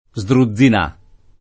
Sdruzzinà [ @ dru zz in #+ ]